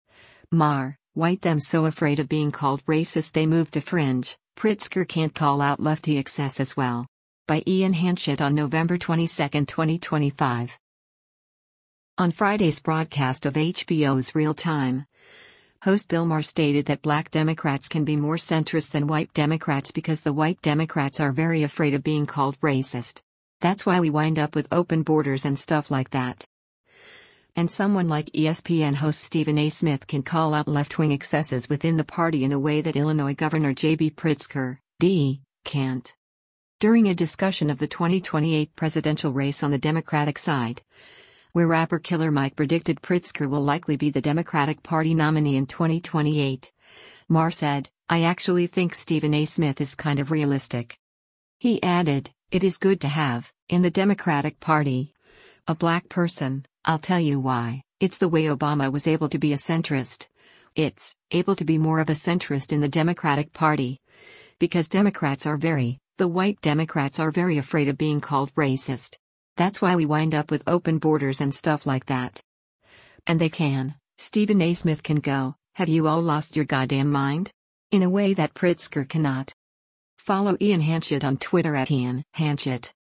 On Friday’s broadcast of HBO’s “Real Time,” host Bill Maher stated that black Democrats can be more centrist than white Democrats because “the white Democrats are very afraid of being called racist. That’s why we wind up with open borders and stuff like that.” And someone like ESPN host Stephen A. Smith can call out left-wing excesses within the party in a way that Illinois Gov. JB Pritzker (D) can’t.